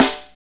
SNR H H S04L.wav